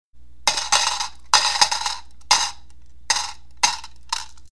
coin drop
coin-drop.mp3